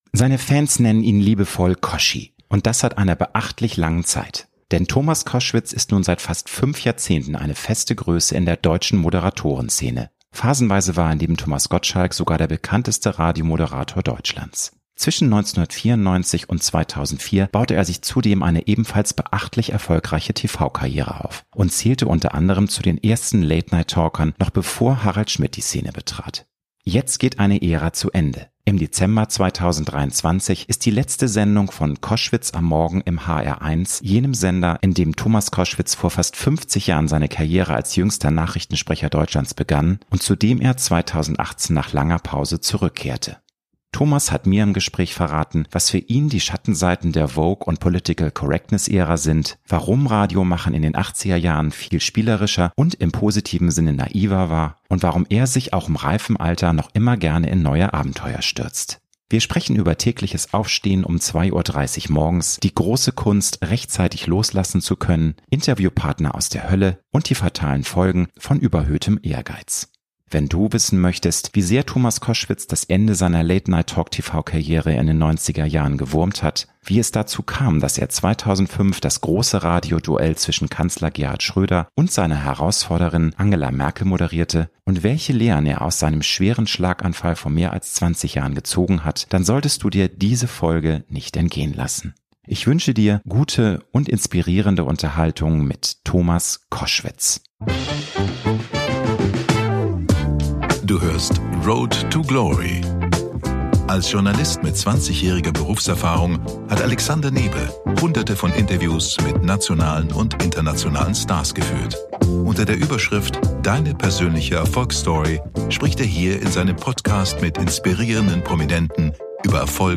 Promi-Talk